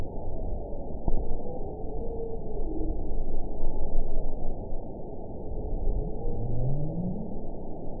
event 921107 date 04/28/24 time 16:52:42 GMT (1 year, 1 month ago) score 9.51 location TSS-AB05 detected by nrw target species NRW annotations +NRW Spectrogram: Frequency (kHz) vs. Time (s) audio not available .wav